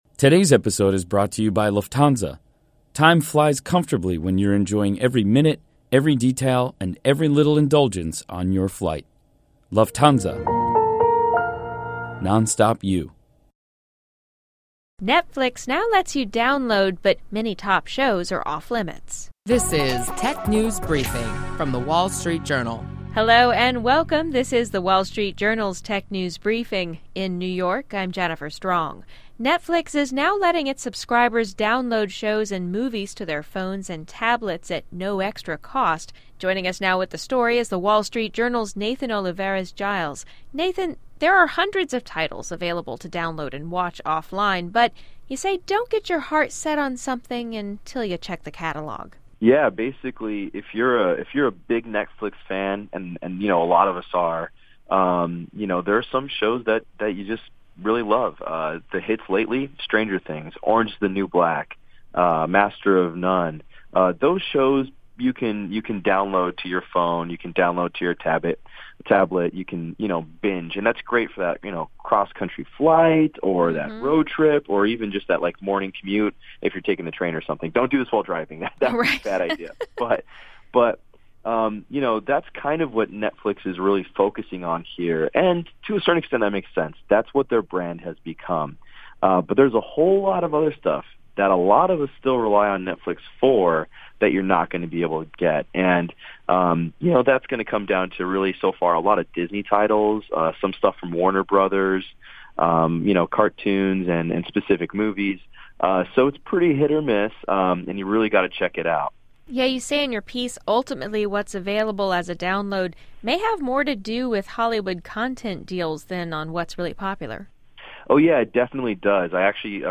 Stay informed on the latest technology trends with daily insights on what’s hot and happening in the world of technology. Listen to our WSJD reporters discuss notable tech company news, new tech gadgets, personal technology updates, app features, start-up highlights and more.